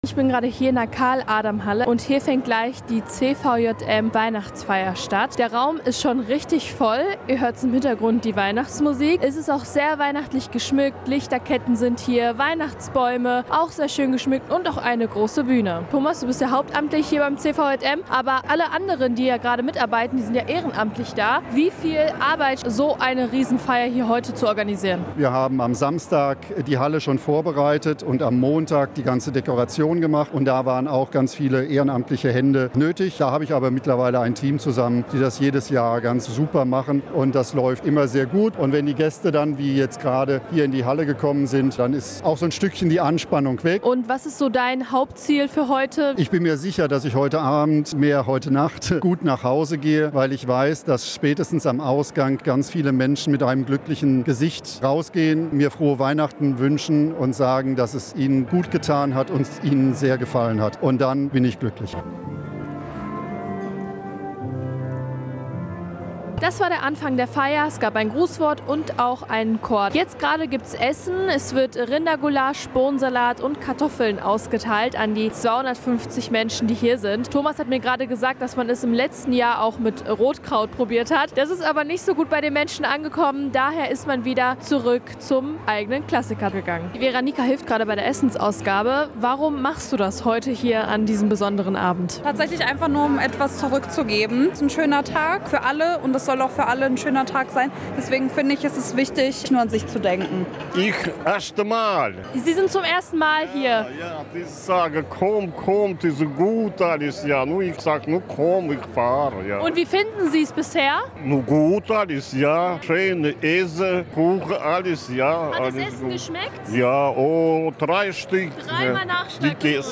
Veranstaltungsort war die Karl-Adam-Halle im Stadtteil Vorhalle.
reportage-cvjm-feier.mp3